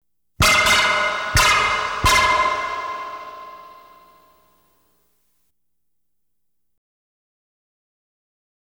Light Beam Hits Sound Effect
Download a high-quality light beam hits sound effect.
light-beam-hits-1.wav